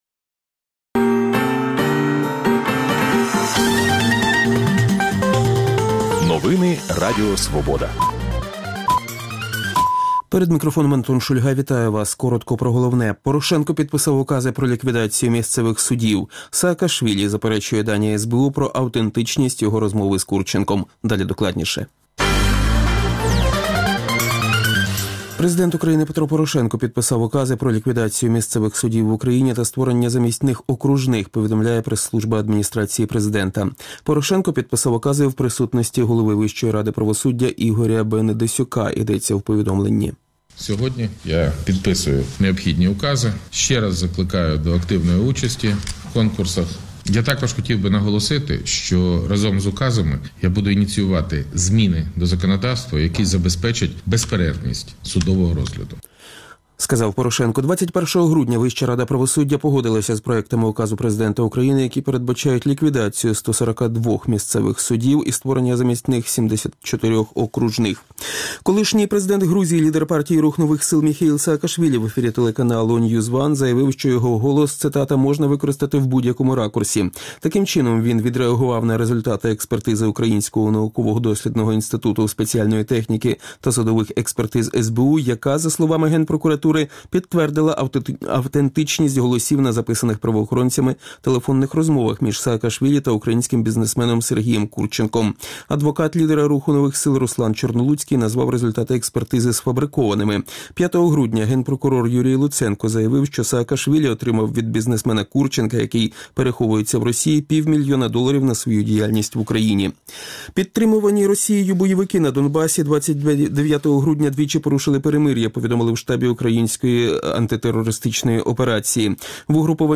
*Яким був 2017 для мешканців окупованого Донбасу? *Інтерв’ю з кандидатом у президенти Росії Ксенією Собчак; *Відродження Різдвяних та новорічних традицій у Львові.